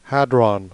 Ääntäminen
Ääntäminen US : IPA : [ˈhæd.ɹɑn] Haettu sana löytyi näillä lähdekielillä: englanti Käännös Substantiivit 1.